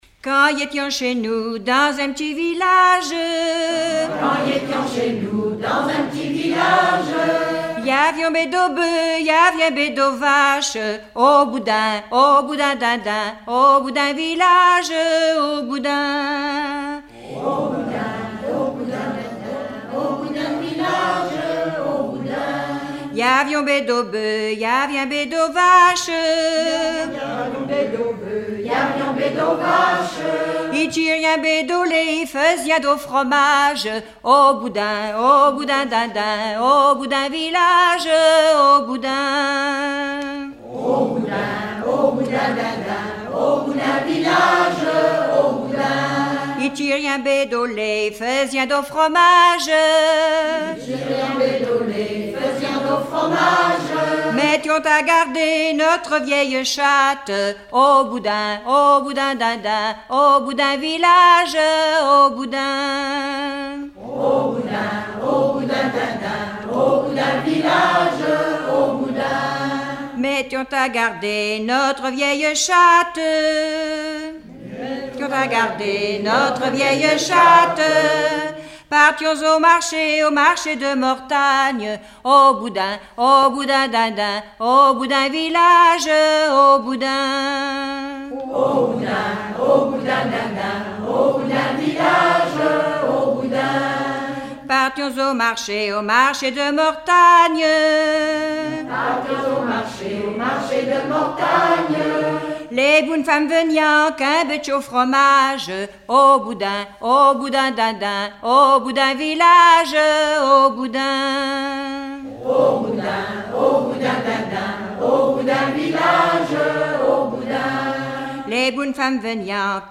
Epesses (Les)
Genre laisse
Catégorie Pièce musicale inédite